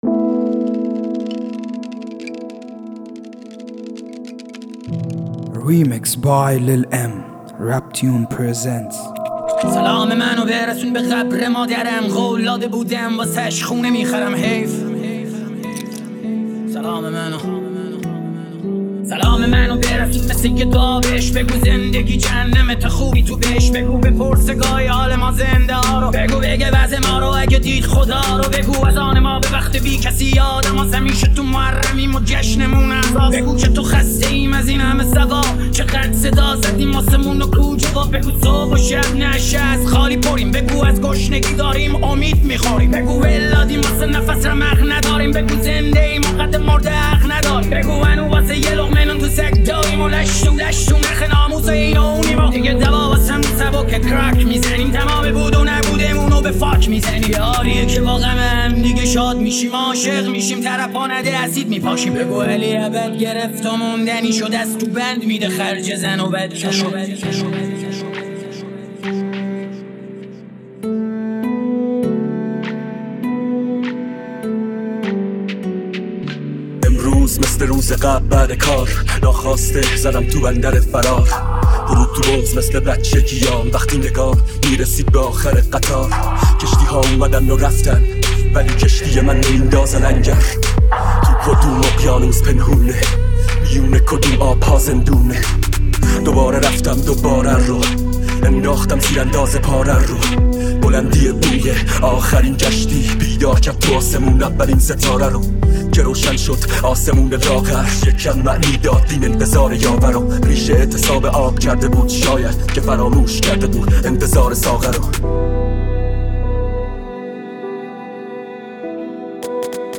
ژانر: رپ
توضیحات: بهترین ریمیکس های رپ فارسی